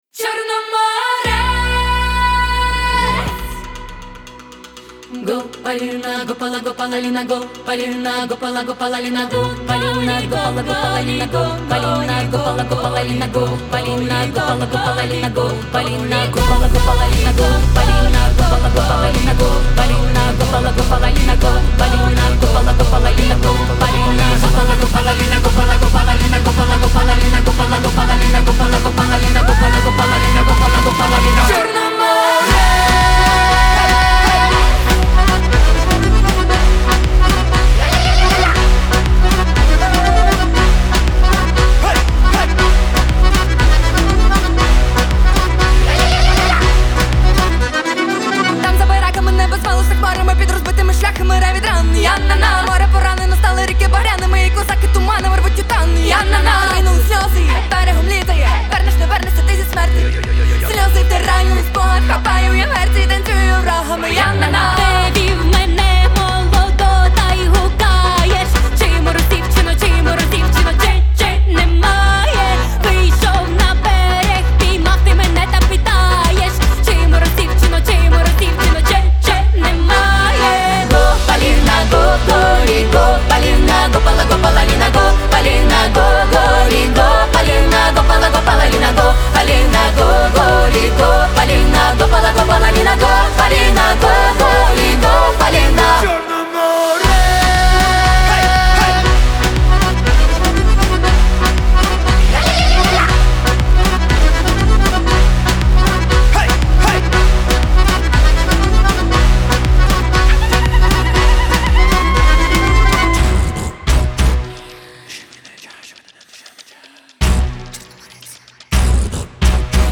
Жанр: Узбекские песни